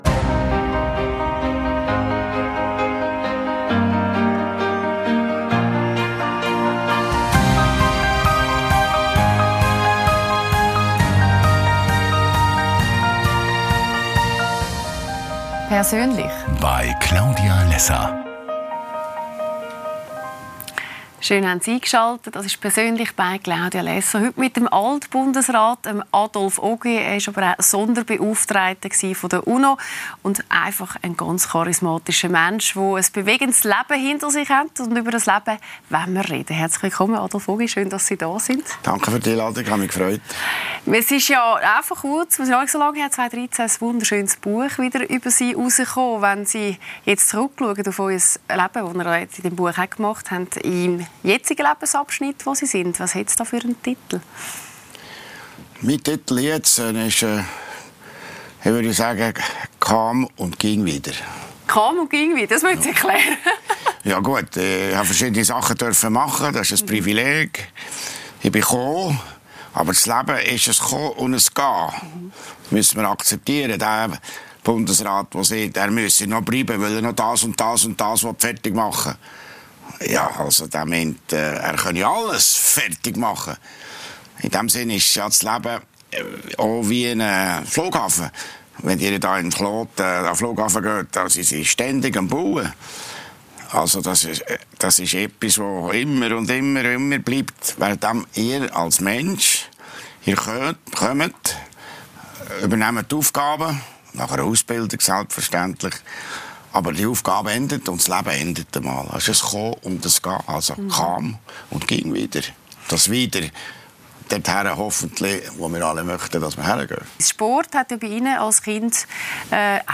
Lässer Classics – mit Adolf Ogi ~ LÄSSER ⎥ Die Talkshow Podcast